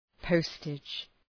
{‘pəʋstıdʒ}